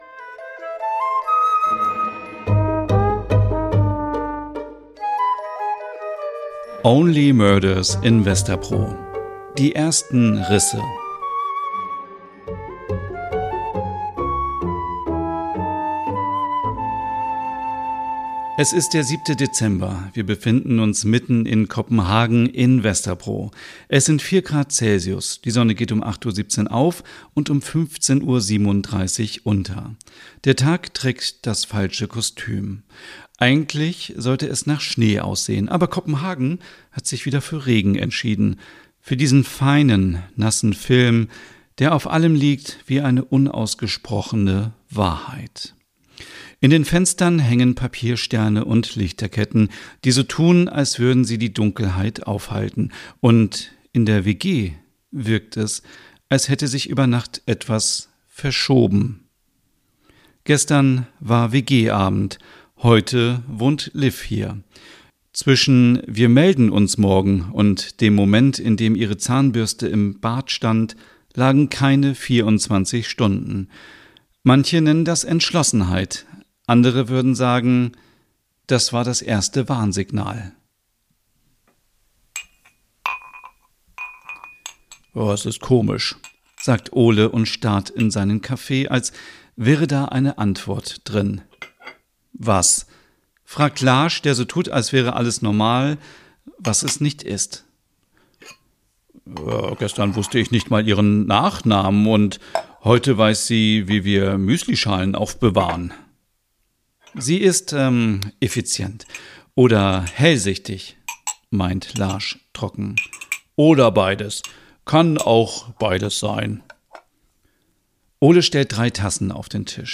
Dann entsteht Only Murders in Vesterbro: ein weihnachtliches Crime-Hörspiel voller nordischer Atmosphäre, schräger Charaktere und warmem Erzähler-Ton.